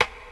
Nep_rim2.wav